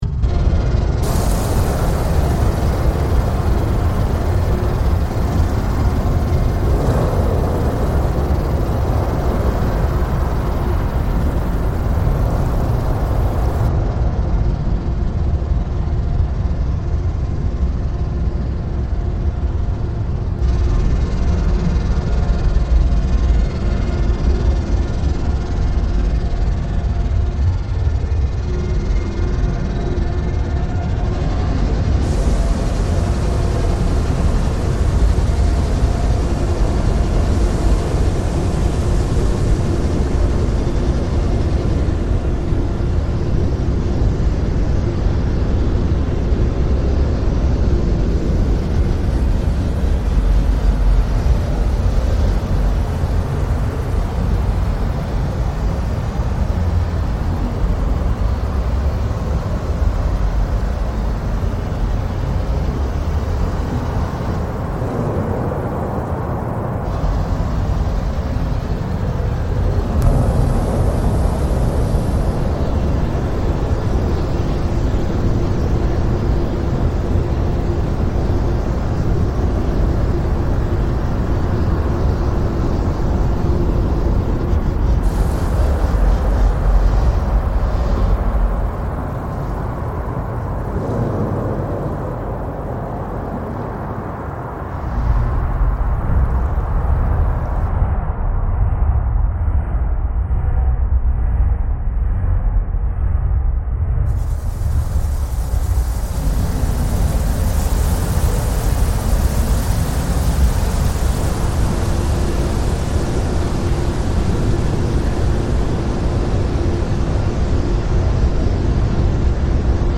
Strokkur geyser reimagined
The piece, using only the original sound file, seeks to delve into the magma chamber and give voice ("Vox" of the title), imagining the forces at play within the interior of the magma chamber and the turbulent interaction of magma heat and water.